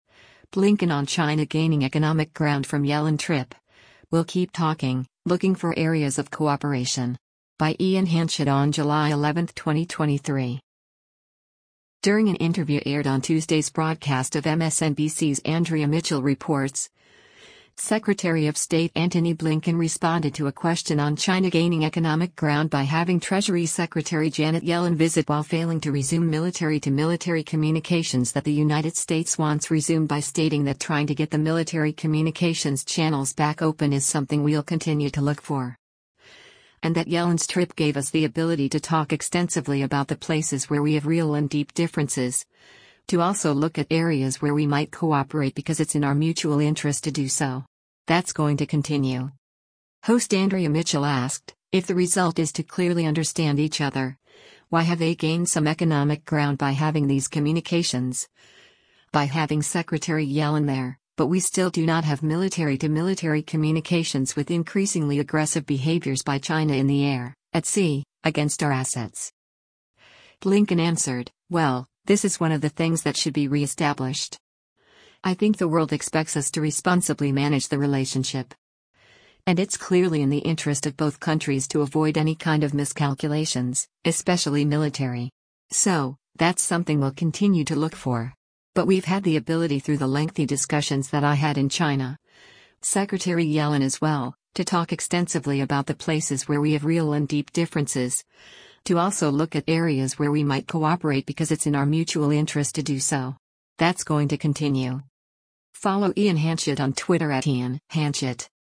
During an interview aired on Tuesday’s broadcast of MSNBC’s “Andrea Mitchell Reports,” Secretary of State Antony Blinken responded to a question on China gaining economic ground by having Treasury Secretary Janet Yellen visit while failing to resume military-to-military communications that the United States wants resumed by stating that trying to get the military communications channels back open is “something we’ll continue to look for.” And that Yellen’s trip gave us the ability “to talk extensively about the places where we have real and deep differences, to also look at areas where we might cooperate because it’s in our mutual interest to do so. That’s going to continue.”